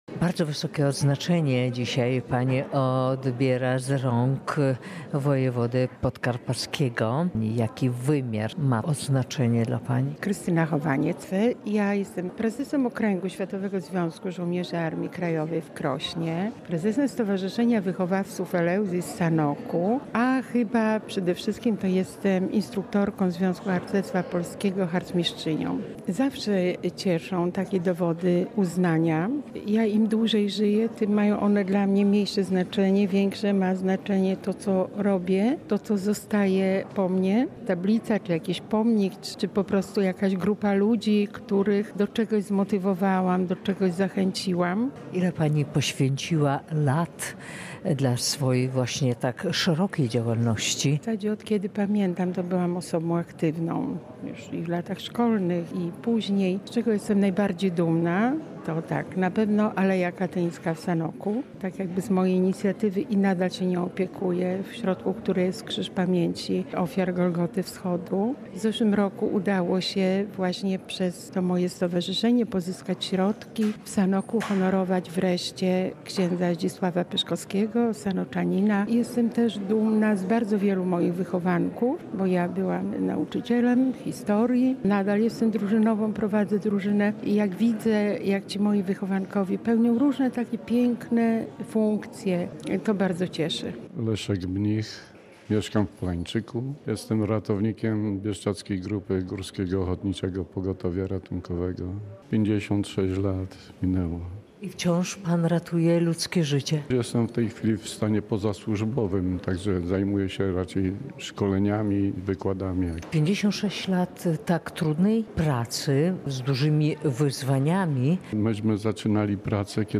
Relacja: